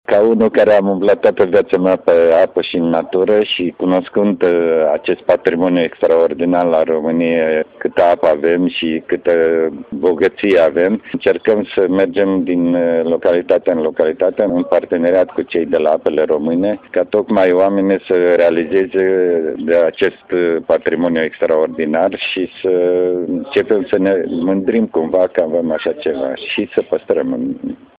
Campania “Descopera Rowmania” are ca scop încurajarea oamenilor să facă mişcare şi sport, spune fostul campion român, Ivan Paţaichin.
02-PATZAICHIN-MESAJ.mp3